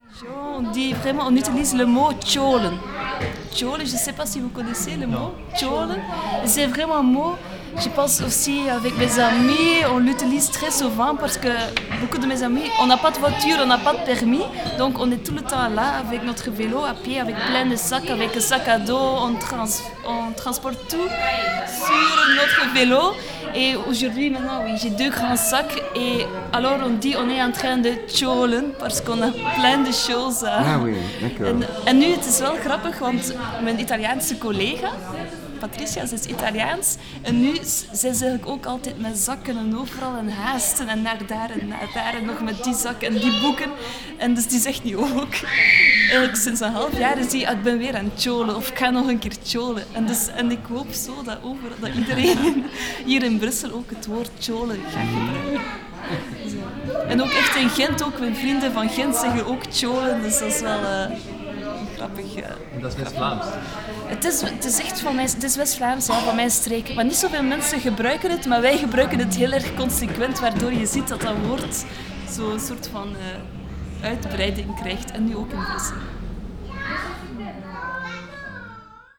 explication